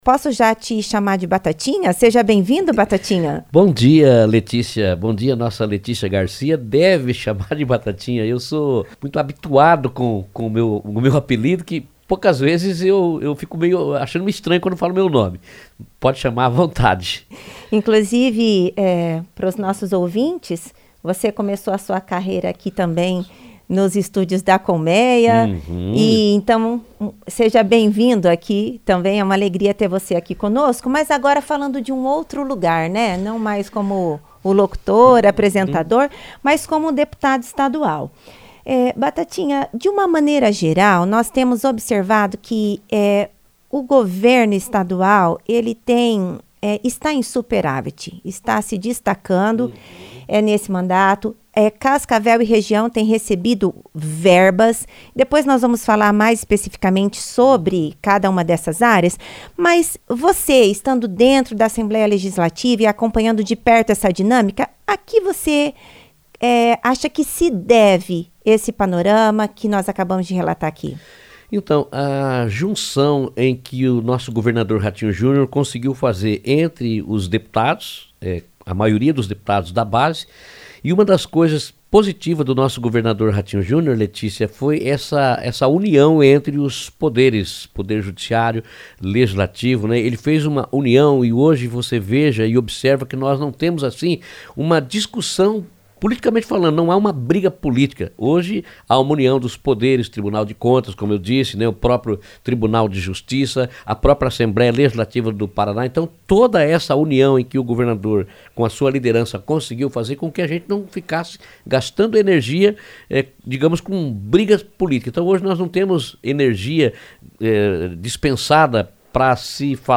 Em entrevista à CBN, o deputado Oziel Luiz (Batatinha) falou sobre a emenda parlamentar mediada por ele, em benefício do Hospital Universitário. Batatinha também ressaltou a pujança da economia paranaense, que acumula cinco superávits desde 2019.